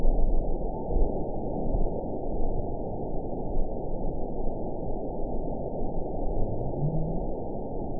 event 917117 date 03/21/23 time 01:04:25 GMT (2 years, 1 month ago) score 9.44 location TSS-AB04 detected by nrw target species NRW annotations +NRW Spectrogram: Frequency (kHz) vs. Time (s) audio not available .wav